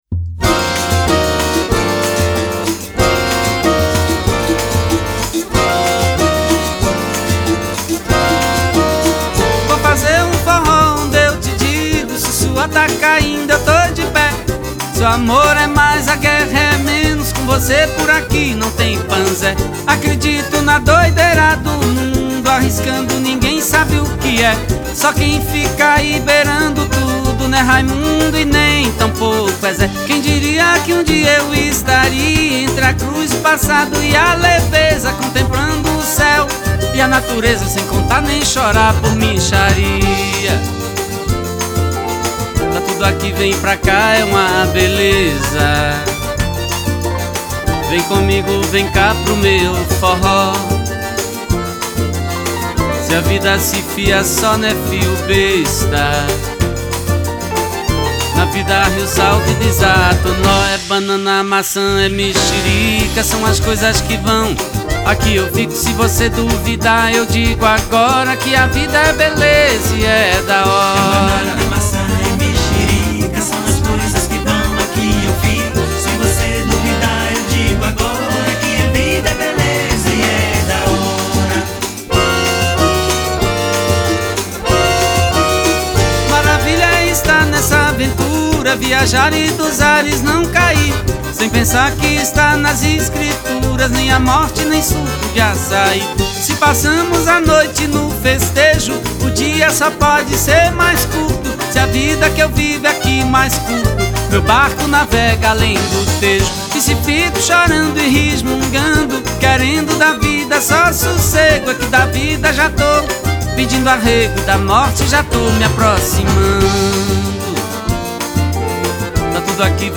Genre : Forró